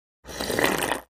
Звуки еды
Человек сербает